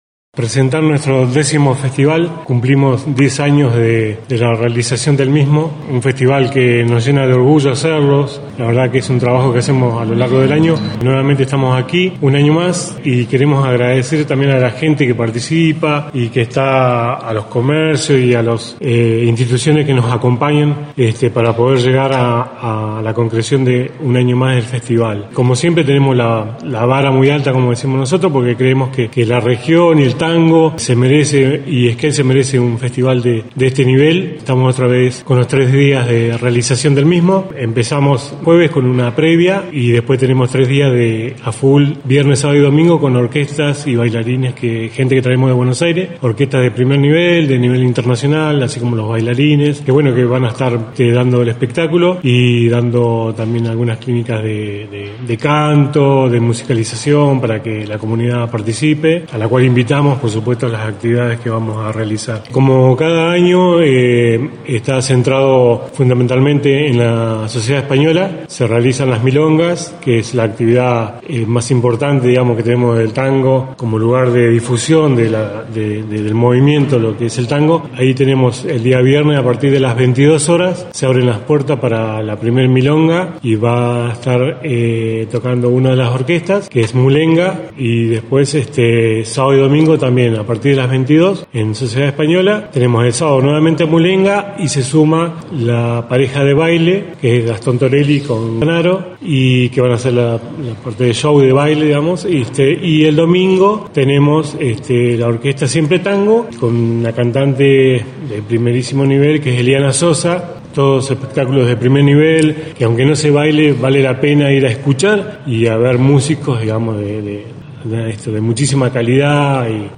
En conferencia de prensa